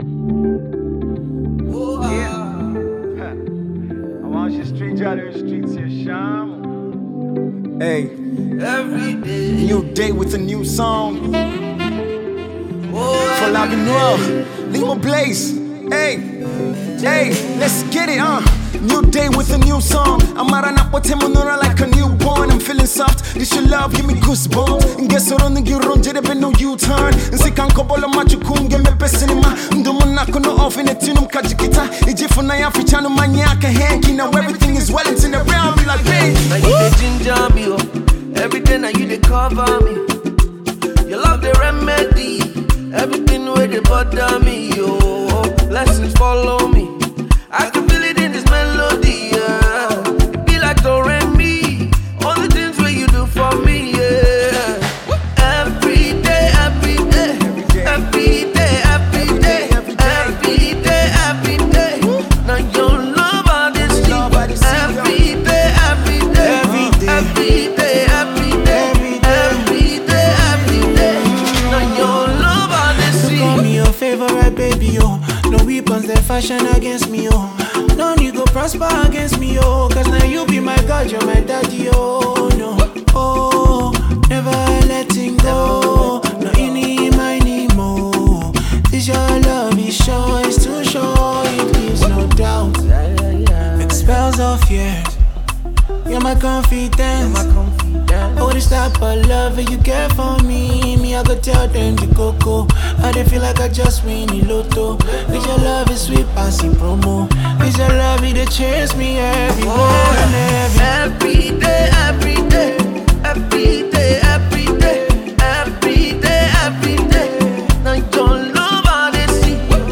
Nigeria’s fast rising Gospel artist